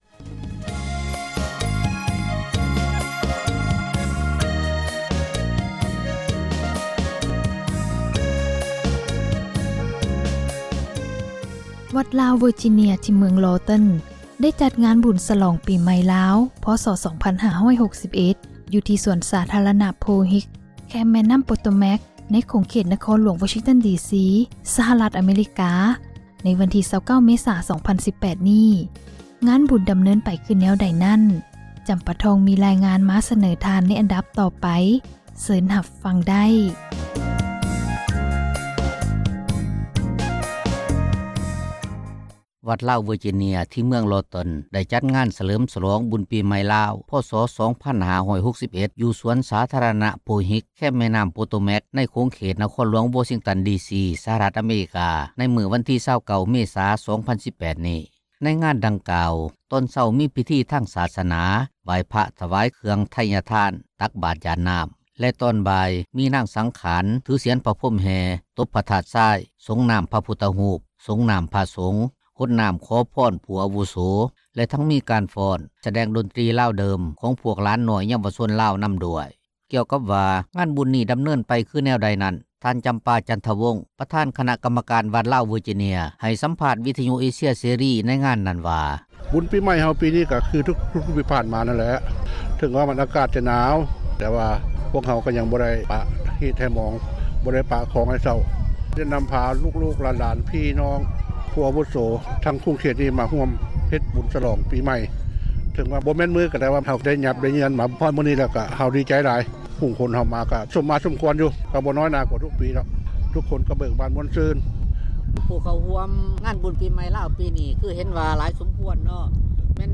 ວັດລາວເວີຈິເນັຍ ທີ່ເມືອງ Lorton ໄດ້ຈັດງານບຸນສເລີມສລອງ ປີໃໝ່ລາວ ພ.ສ. 2561 ຢູ່ສວນສາທາຣະນະ Pohick ແຄມແມ່ນໍ້າ Potomac ໃນຂົງເຂດນະຄອນຫຼວງ ວໍຊິງຕັນ ດີຊີ ສະຫະຣັຖ ອາເມຣິກາ ໃນມື້ວັນທີ 29 ເມສາ 2018 ນີ້.